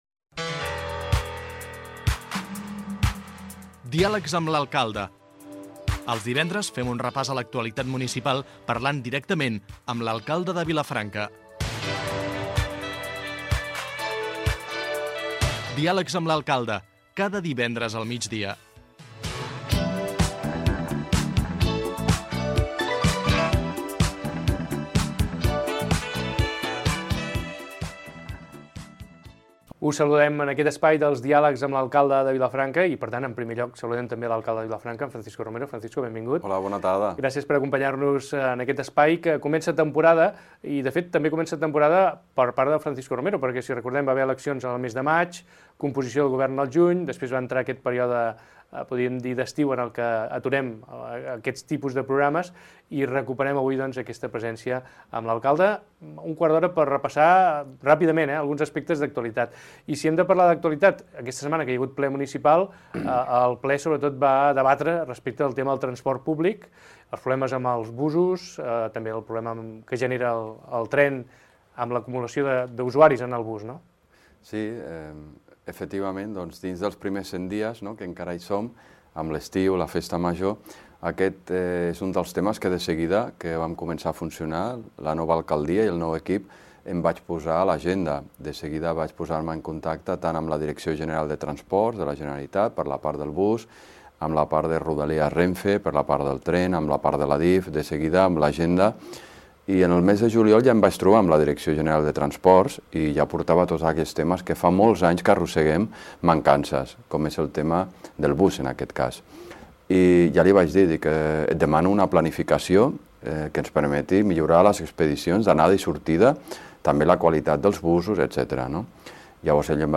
Careta del programa, presentació i pregunta a l'alcalde de Vilafranca del Penedès Francisco Romero sobre el servei públic d'autobusos
Informatiu